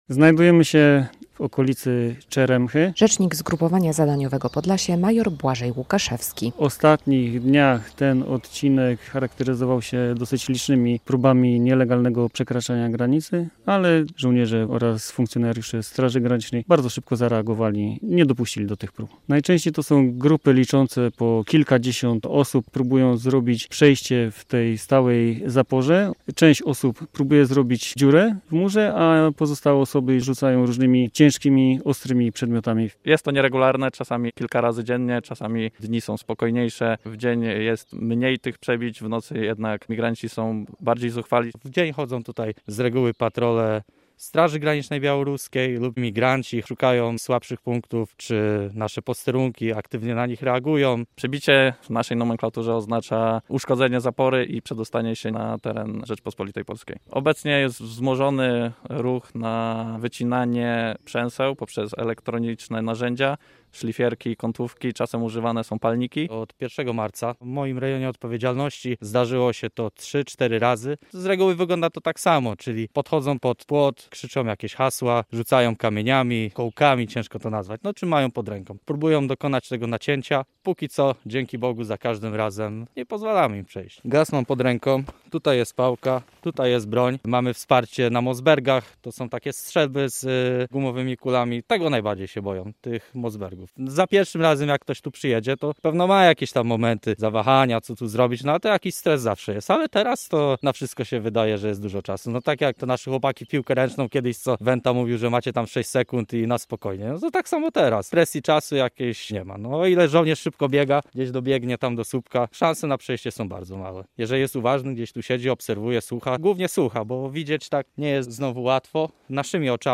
Opowiedzieli nam żołnierze [zdjęcia, wideo]
relacja